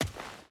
Dirt Run 2.ogg